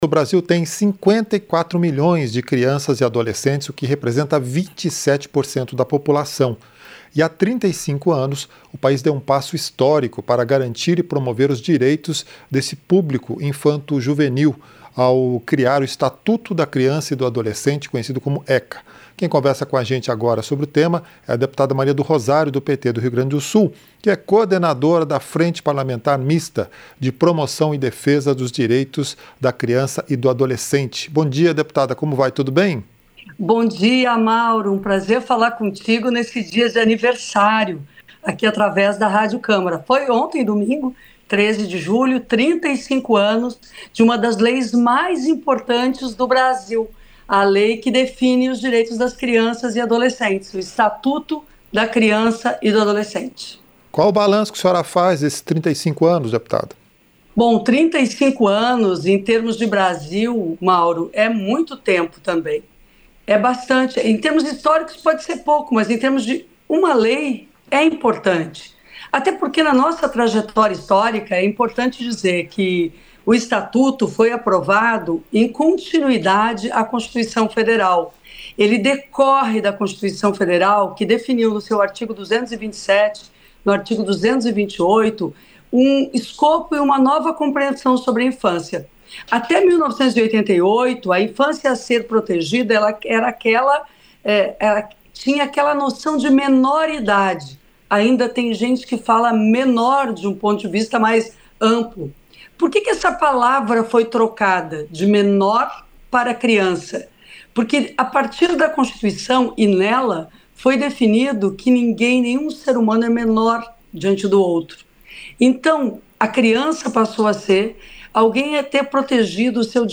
Entrevista - Dep. Maria do Rosário (PT-RS)